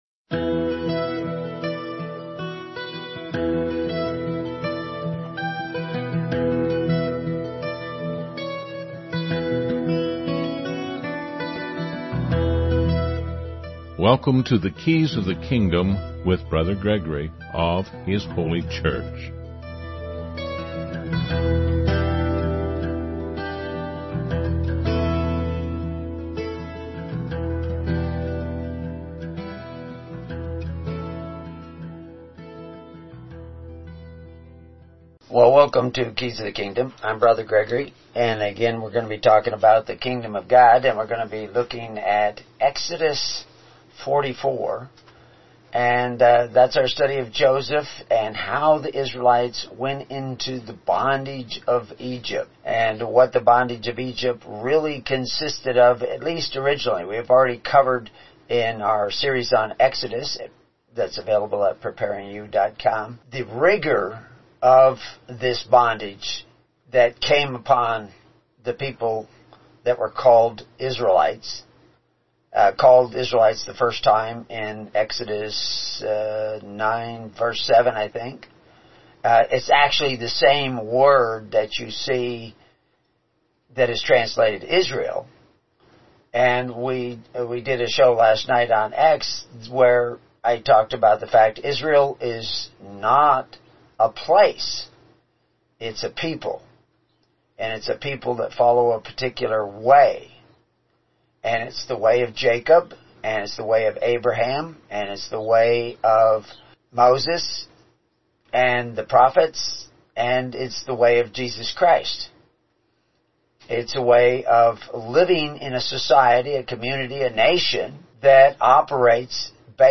"Keys of the Kingdom" is broadcast weekly (Saturday mornings - 10AM Central Time) on First Amendment Radio.